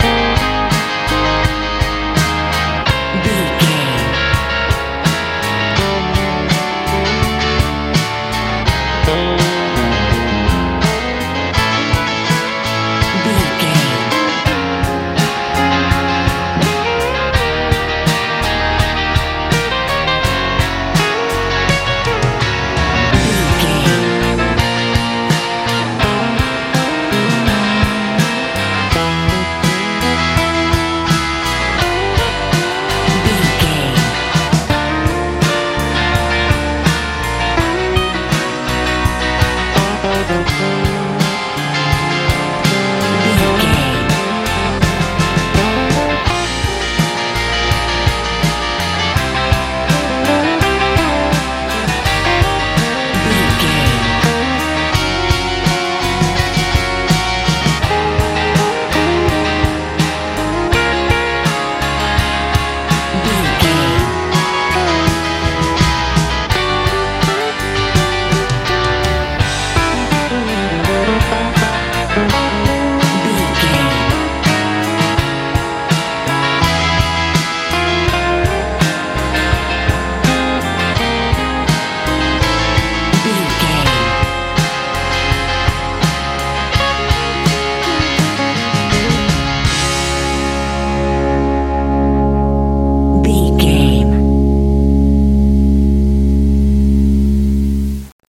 pop rock feel
Ionian/Major
A♭
fun
playful
bass guitar
drums
electric guitar
sweet